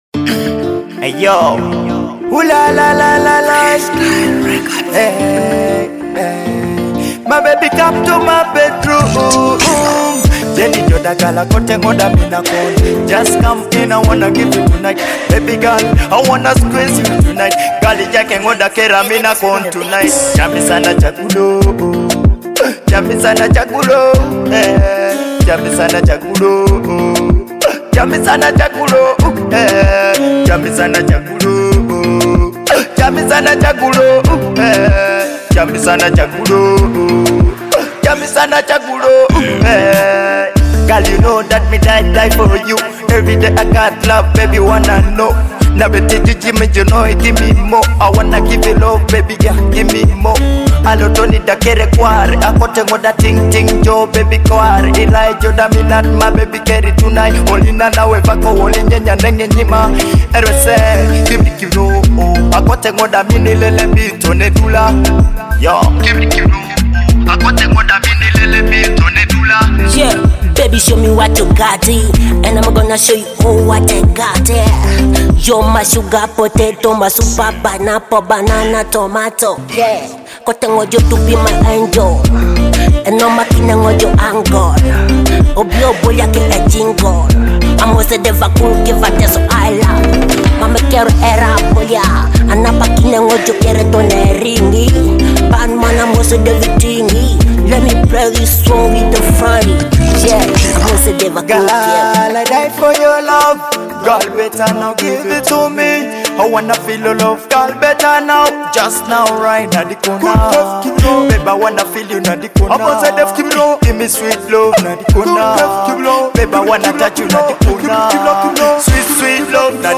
an upbeat African track with Afrobeat and dancehall rhythms.
your premier destination for Teso music!